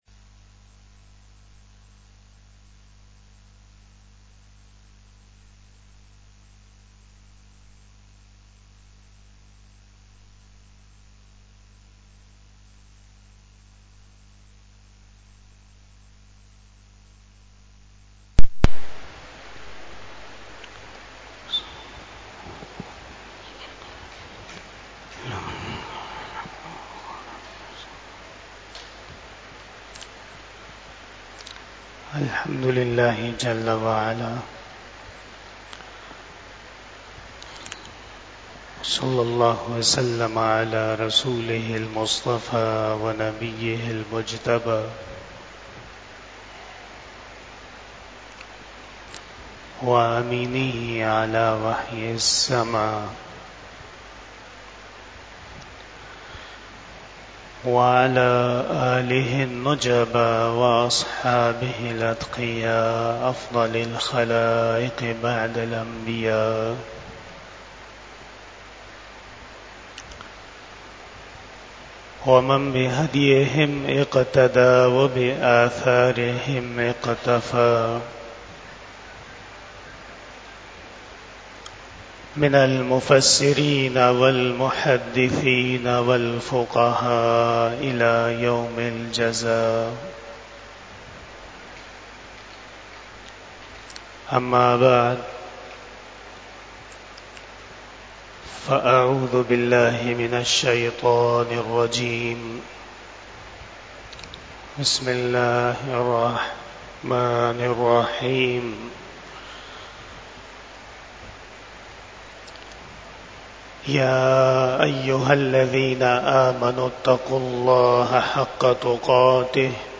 بیان جمعۃ المبارک 30 ربیع الاول 1446ھ بمطابق 04 اکتوبر 2024ء
Khitab-e-Jummah